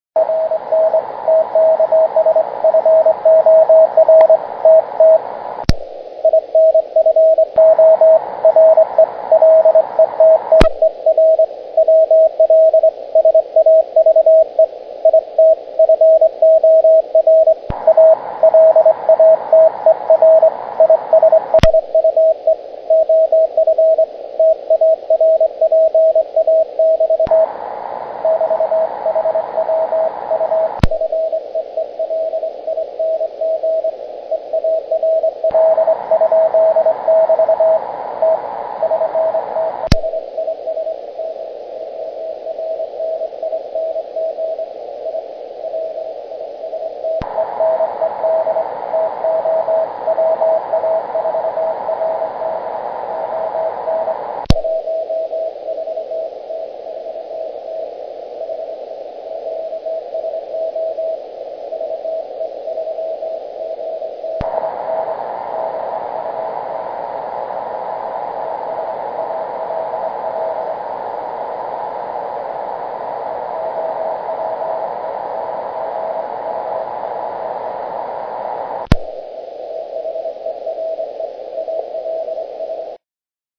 A longer file, copying a CW signal that has some QSB, with some line noise.  The filter is switched in and out multiple times.  This file demonstrates very well the effect of also removing white noise.